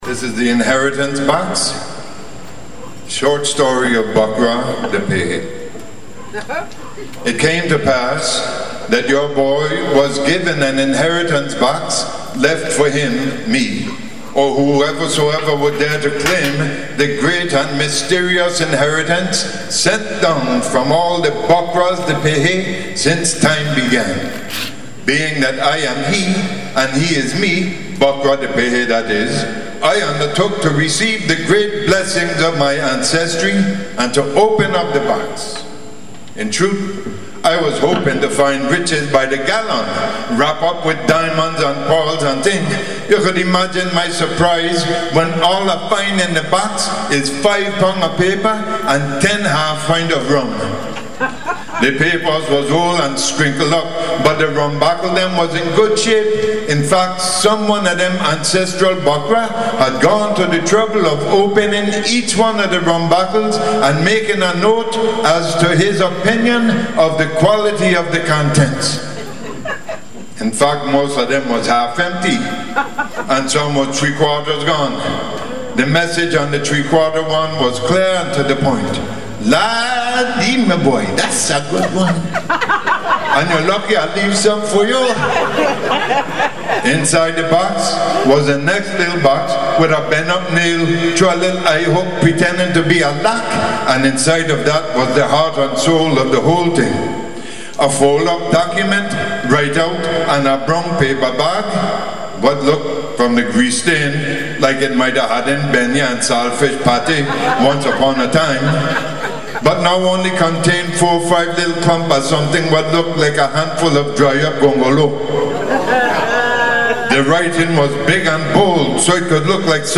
LIVE in St Thomas
recorded live in my Concert version
Calypso Comedy